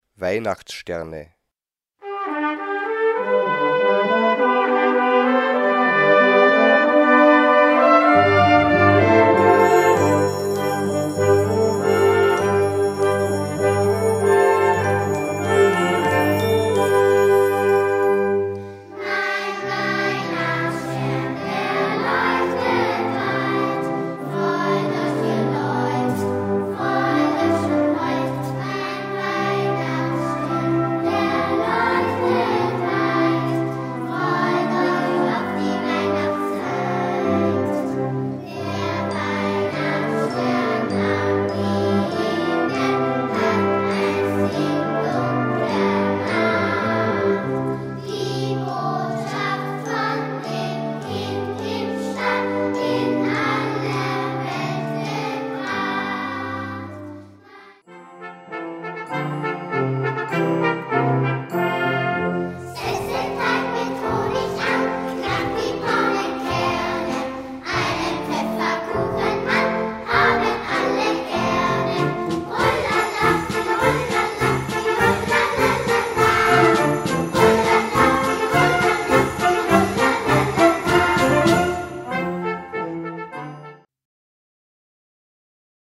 Gattung: Kinderweihnachtsliederpotpourri
Besetzung: Blasorchester